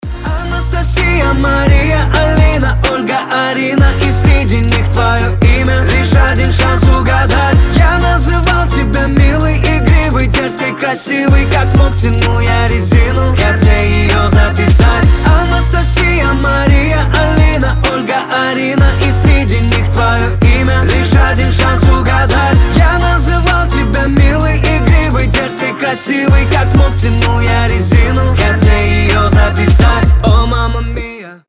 • Качество: 320, Stereo
поп
веселые